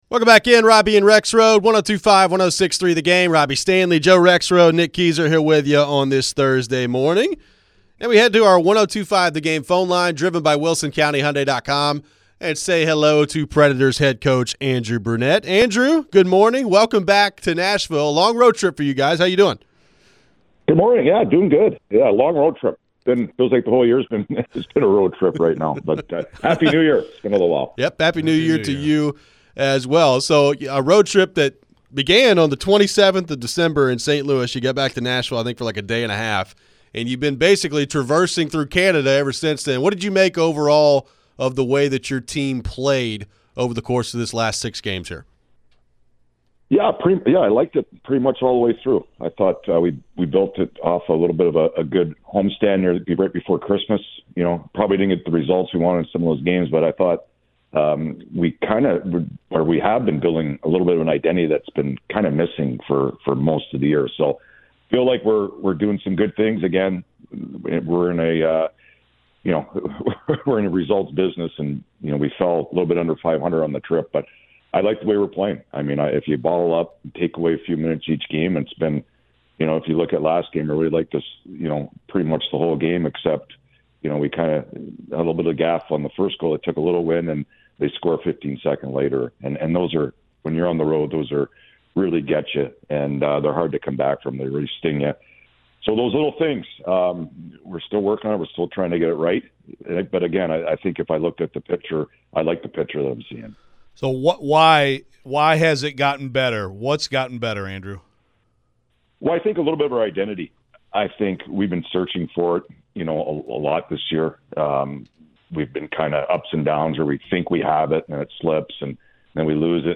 Andrew Brunette Interview (1-9-25)
Headliner Embed Embed code See more options Share Facebook X Subscribe Nashville Predators head coach Andrew Brunette joined the show after a six-game road trip. What did he see in terms of progress with his group? How has Filip Forsberg looked despite not scoring a lot in the last month?